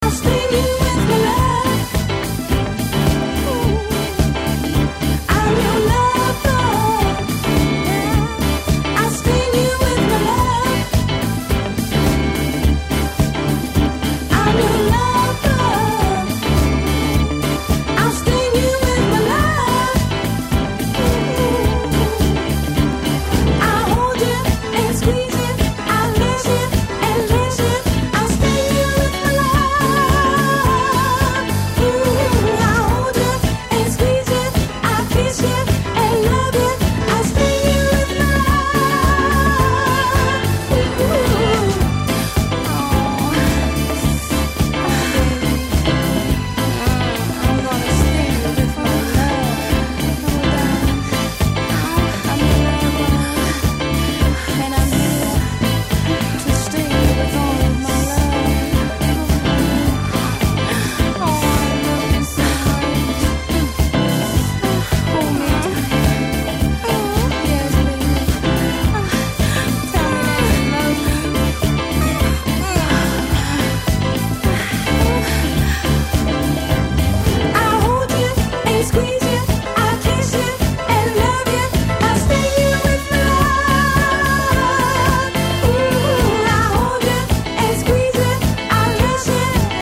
Disco Soul Funk